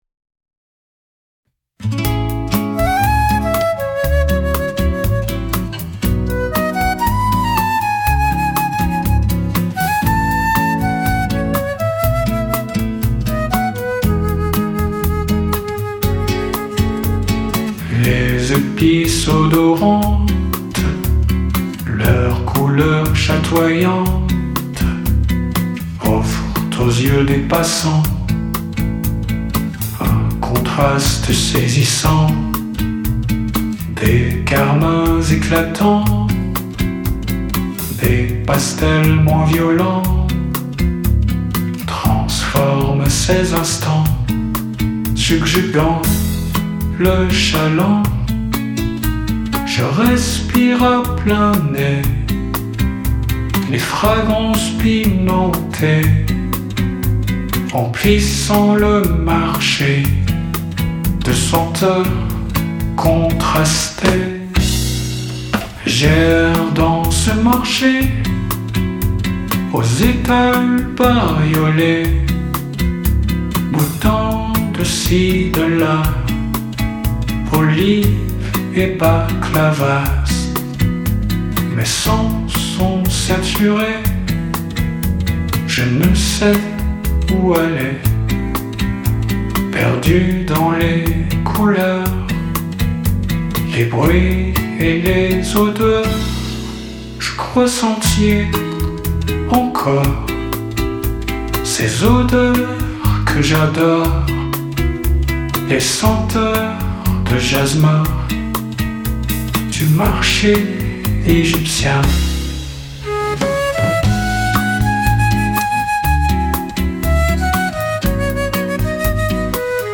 Mais quand je me suis attaqué à la composition, c’est une bossa nova qui m’est spontanément venu dans les doigts.
Et les accords sont un peu jazzy (donc, dans la partition jointe, j’ai utilisé les symboles jazz pour les accords…)